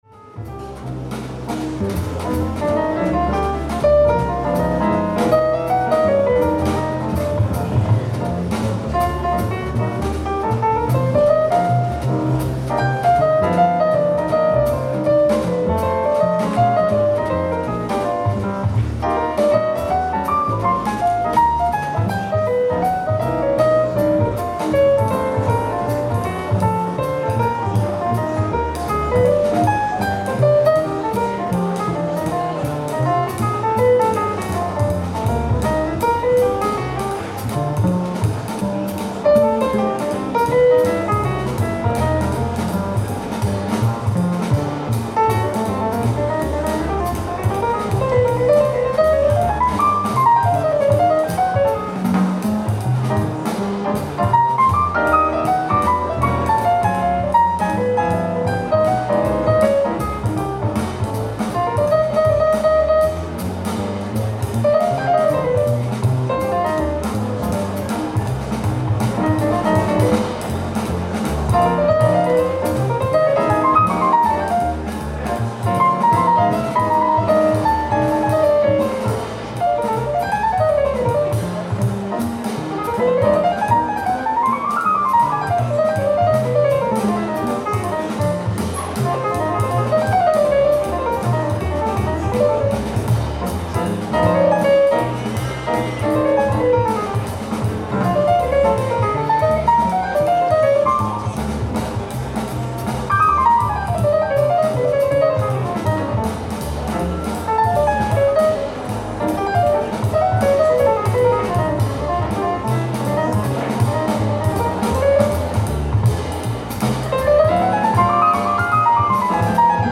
ライブ・アット・マルシアック、フランス 07/31/2006
※試聴用に実際より音質を落としています。